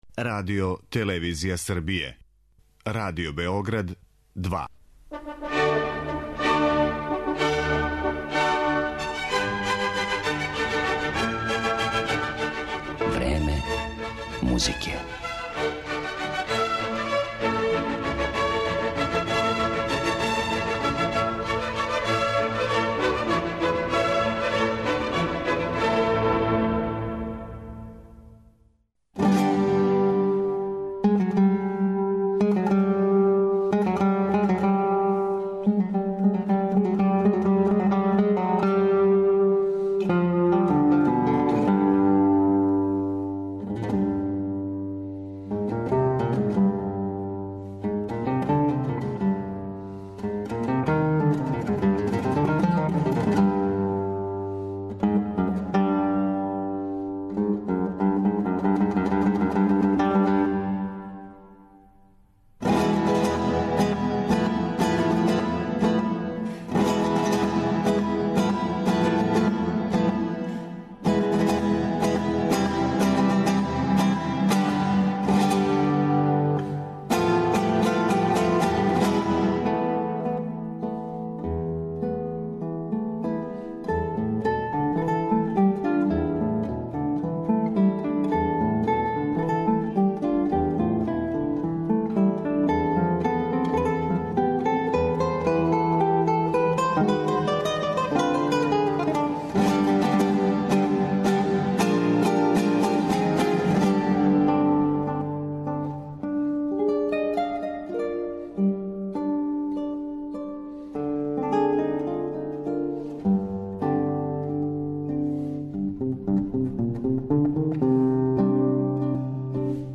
Део његовог широког репертоара чућете у данашњој емисији